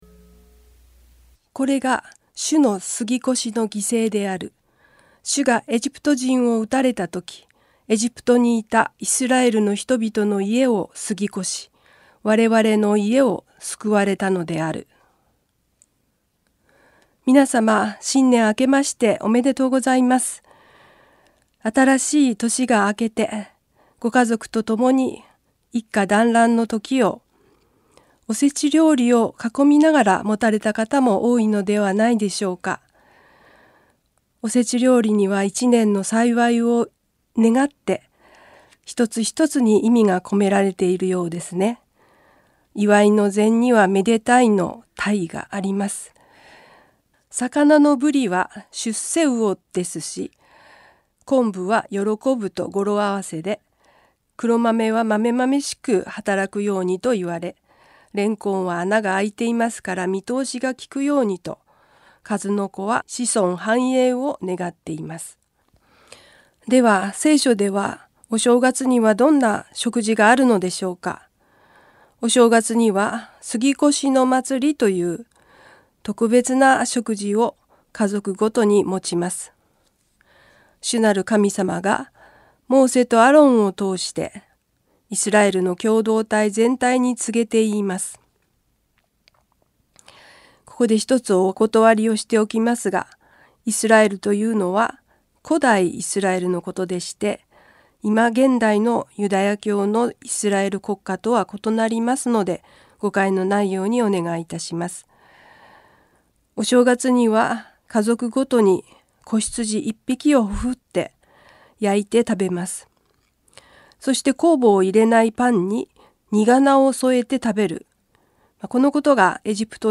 ラジオ番組「キリストへの時間」